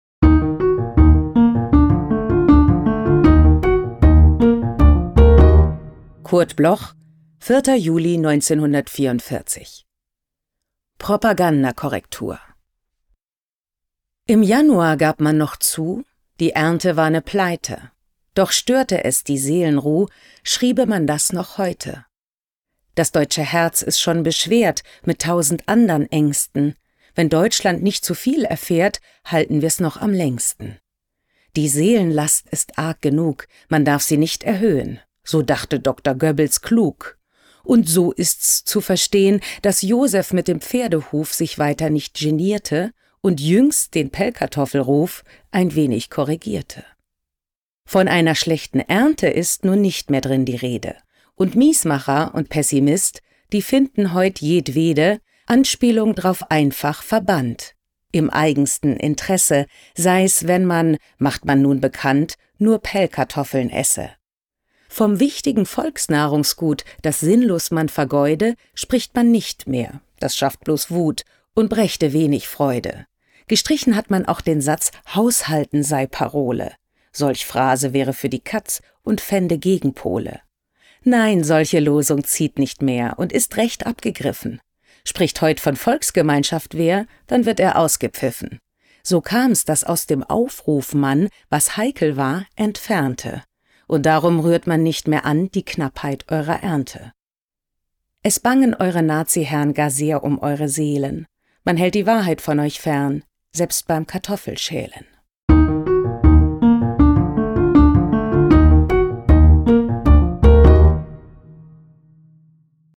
Katharina Wackernagel (* 1978) ist eine deutsche Schauspielerin, Regisseurin, Hörspiel- und Hörbuchsprecherin.
Aufnahme: Killer Wave Studio, Hamburg · Bearbeitung und Musik: Kristen & Schmidt, Wiesbaden
Katharina-Wackernagel-Propagandakorrektur_raw_mit-Musik.m4a